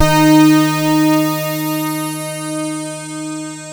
KORG D#4 1.wav